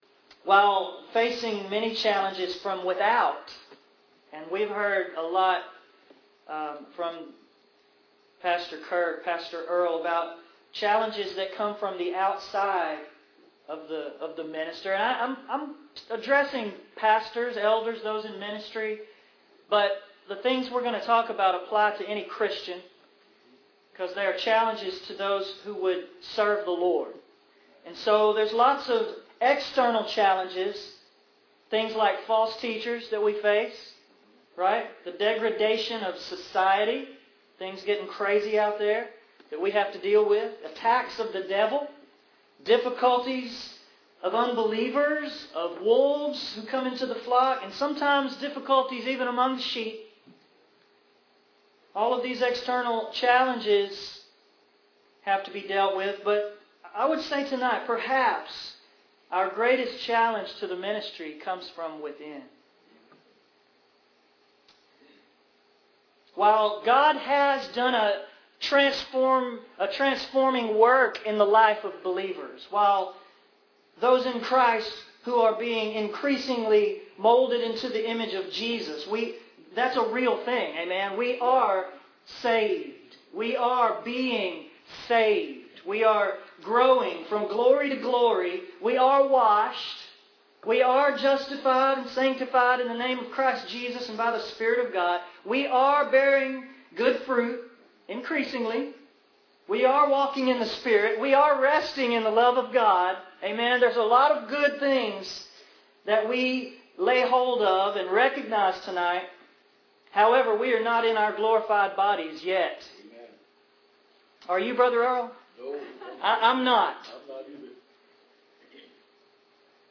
This message was given at the 2014 ACRC conference that focused on Challenges to the Gospel Ministry. We look at the threat from within.